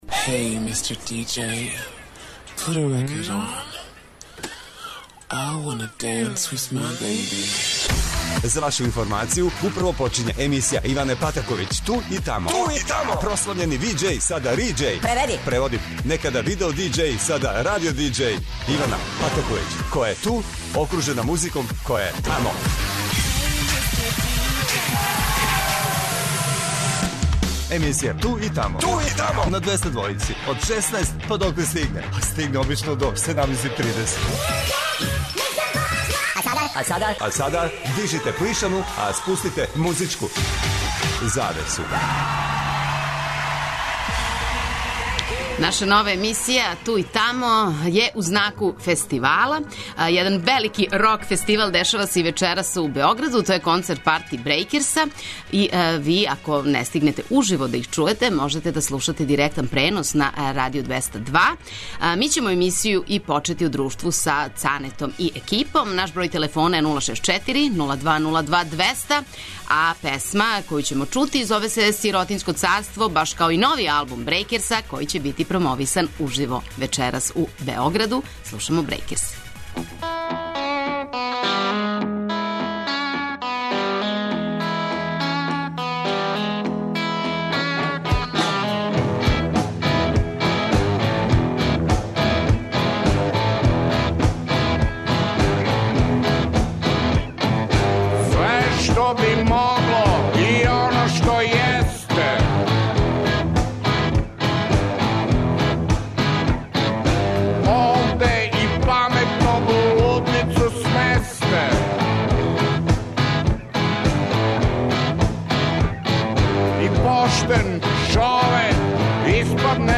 Емисија 'Ту и тамо' је ове суботе у фестивалском фазону. Слушаћемо домаће и стране извођаче који свирају на музичким фестивалима овог лета у Србији.